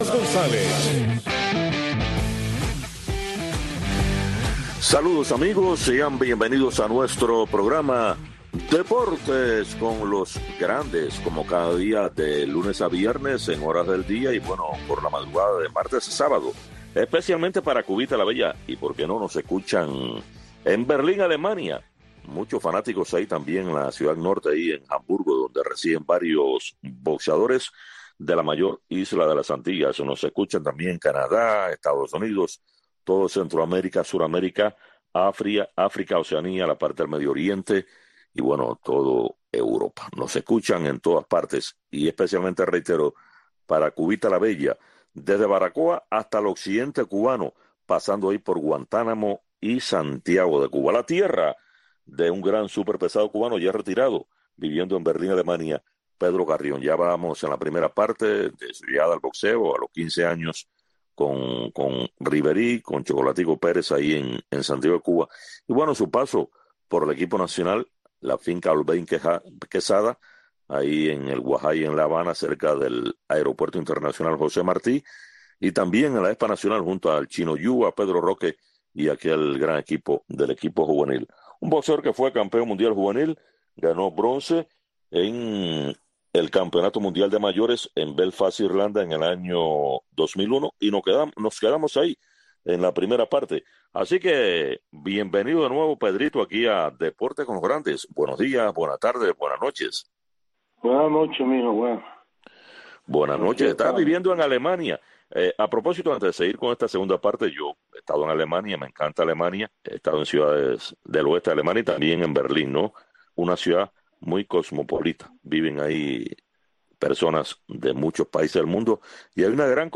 Deportes con los grandes. Un programa de Radio Martí, especializado en entrevistas, comentarios, análisis de los grandes del deporte.